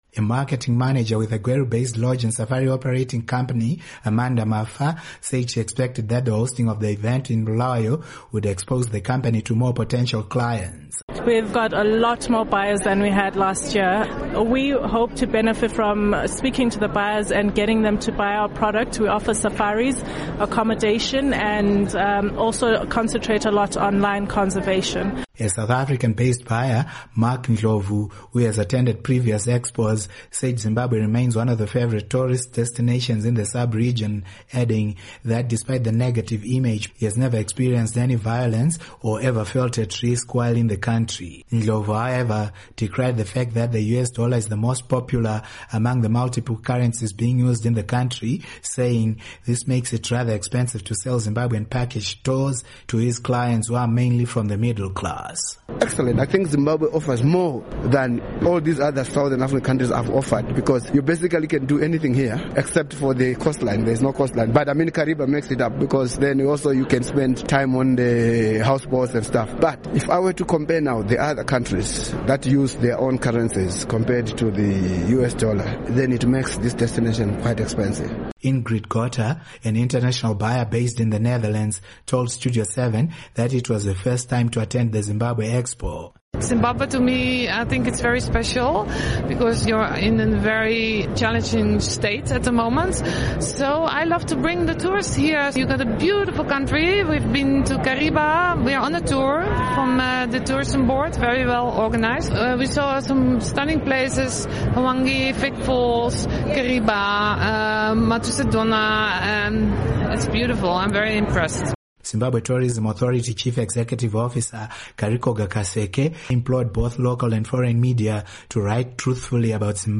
Report on Sanganai/Hlanganani Tourism Expo